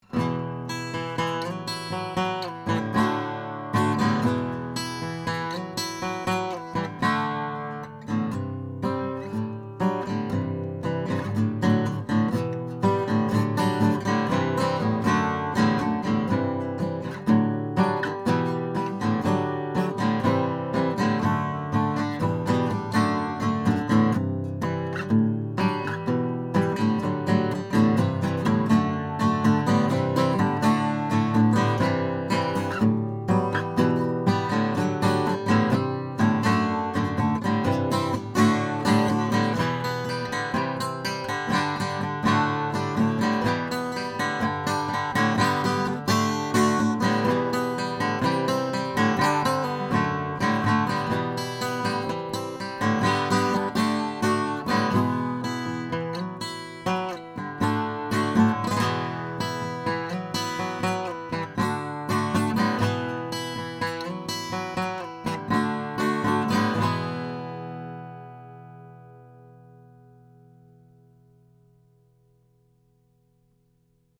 Tracked through a pair of Warm Audio WA12 preamps, into a Metric Halo ULN-8 interface, no compression, EQ or effects:
1933 MARTIN O-17 FLATOP